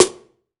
SNARE 063.wav